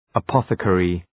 Προφορά
{ə’pɒɵə,kerı}